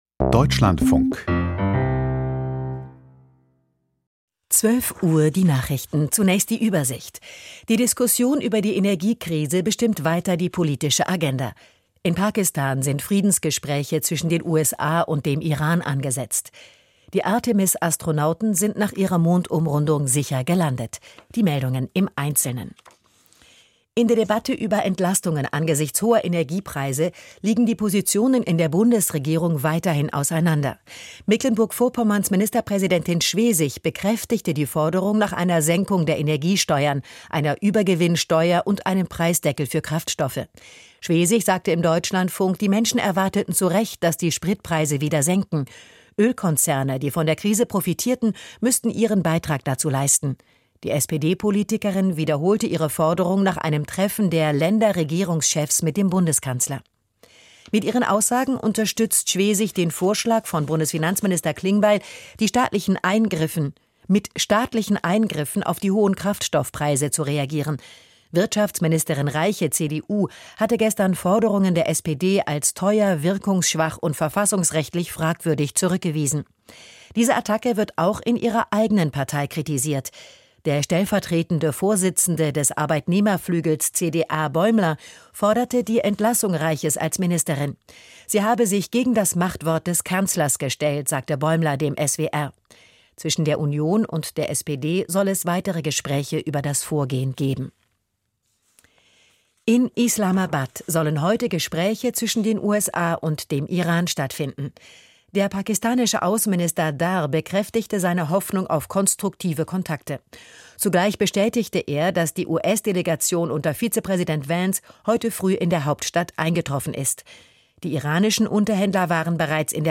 Die Nachrichten vom 11.04.2026, 12:00 Uhr